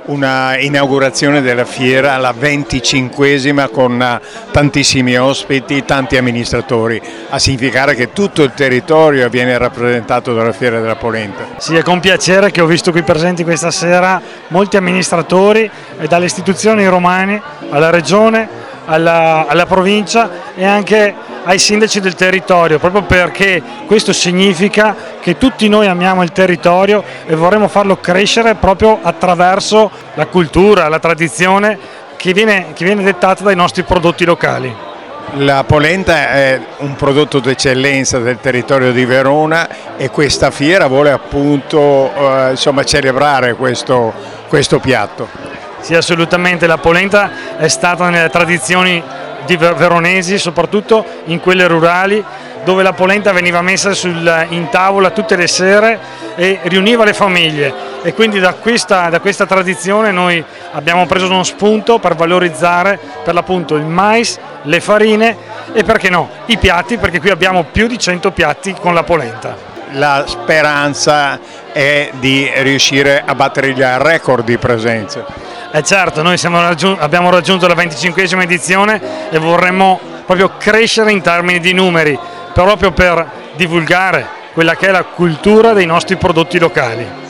Al microfono del nostro corrispondente
Eddi Tosi, sindaco di Vigasio
Eddy-Tosi-sindaco-di-Vigasio-sulla-Fiera-della-Polenta.wav